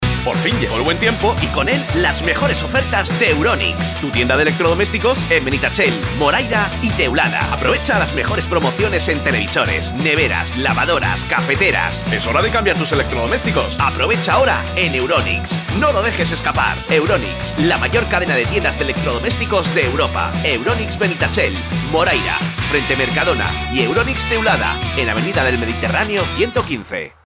Campaña publicitaria para tienda de electrodomésticos